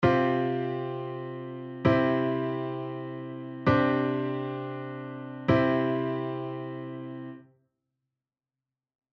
↓の例はCsus4⇒C⇒Csus2⇒Cで、sus4・sus2の後にコードCを鳴らすことで、3度の音に戻っています。
Csus4⇒C⇒Csus2⇒C
ccsus4ccsus2.mp3